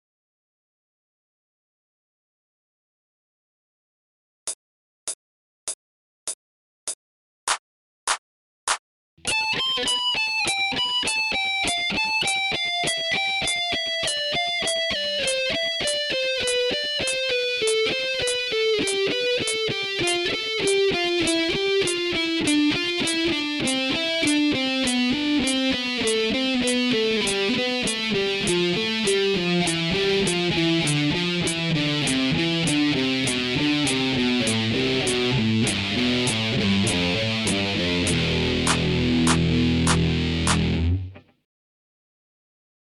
training phrase 1　メジャースケール〜下降フレーズ〜
１弦２０フレットから６弦０フレットまでの下降フレーズです。
４音ずつのよくある下降フレーズですが、あまり４音ずつ区切った意識は持たないほうが良いでしょう。